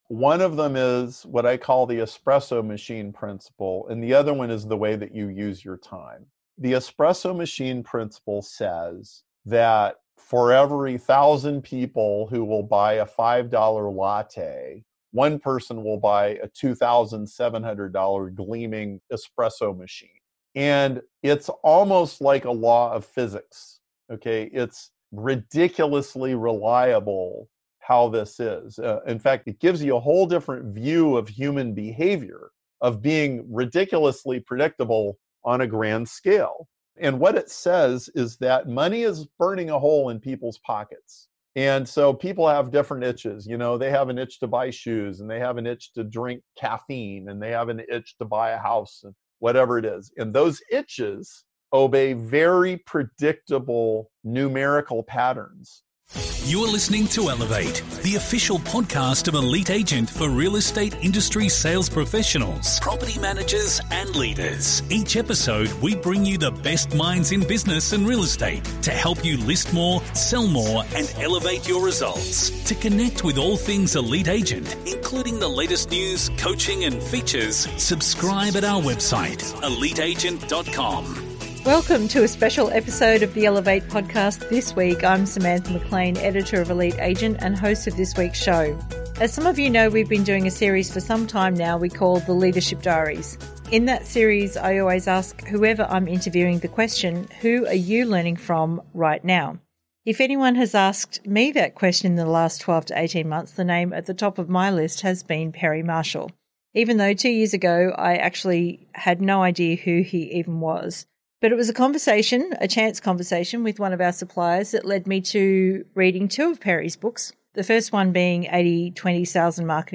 In that series, I always ask whoever I’m interviewing the question, who are you learning from?